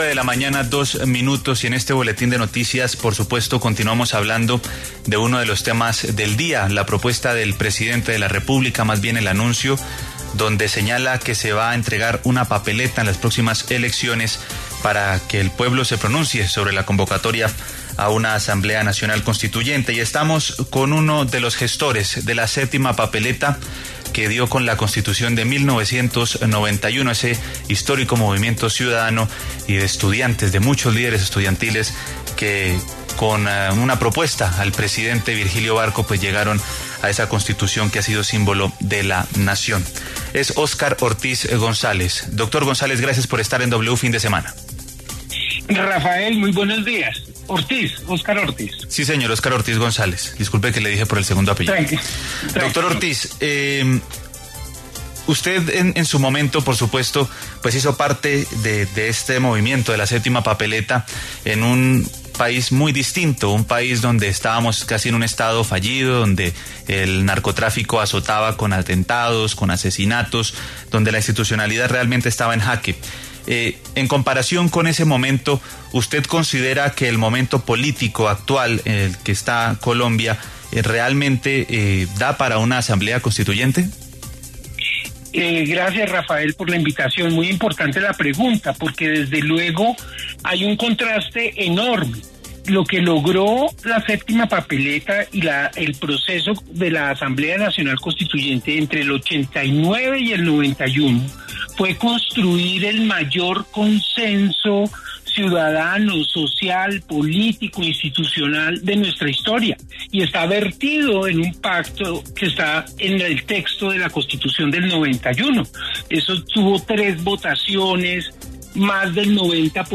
Óscar Ortiz González, exzar Anticorrupción y gestor de la séptima papeleta que ordenó la expedición de la Constitución de 1991, habló en W Fin De Semana sobre la propuesta del presidente Gustavo Petro, que planteó la posibilidad de convocar a una Asamblea Nacional Constituyente en las elecciones de 2026.